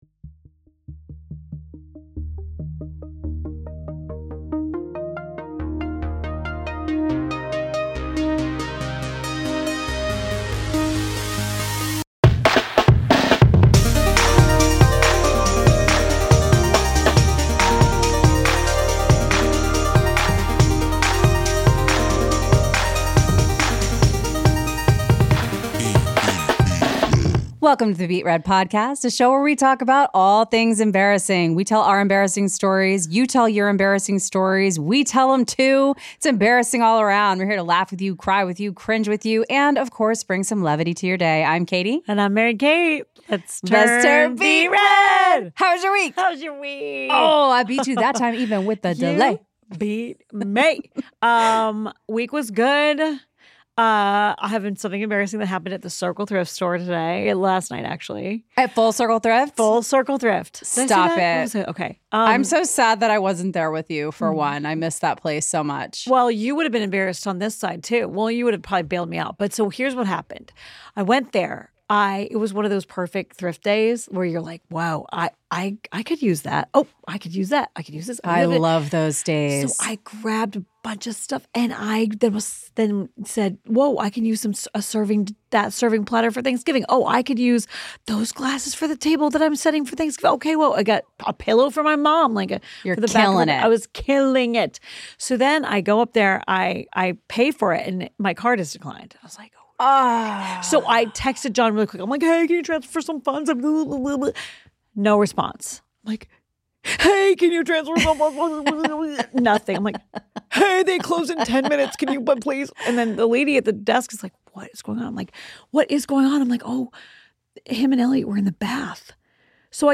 at PROJKT studios in Monterey Park, CA.